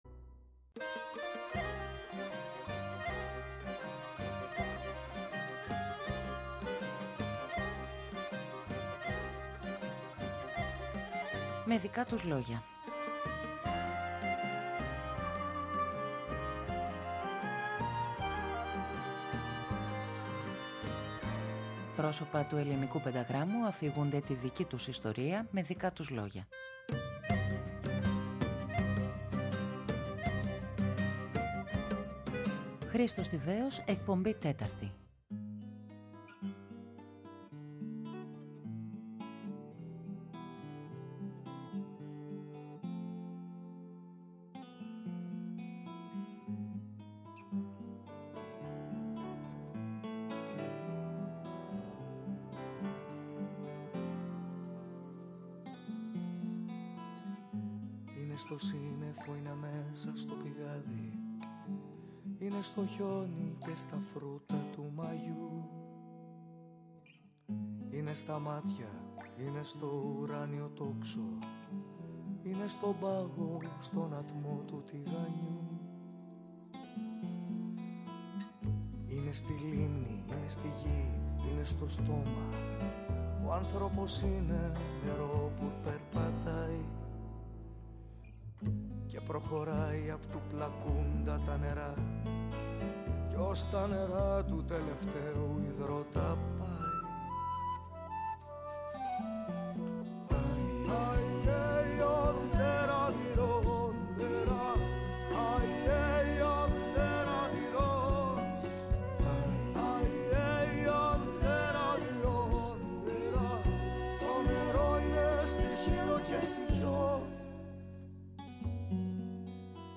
Πρόσωπα του πενταγράμμου αφηγούνται τη δική τους ιστορία…
ΔΕΥΤΕΡΟ ΠΡΟΓΡΑΜΜΑ Με Δικα τους Λογια Μουσική Συνεντεύξεις